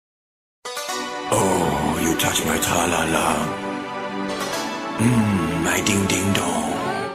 Здесь вы найдете забавные, мотивирующие и необычные аудиоэффекты, которые можно использовать при получении донатов.